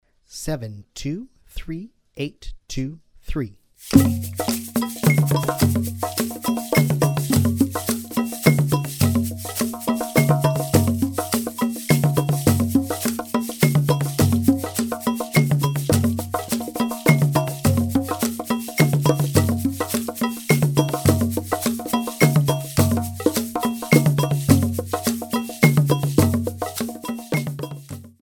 The music combines various percussion instruments,
Slow Triple Meter
Slow Triple Meter - 105 bpm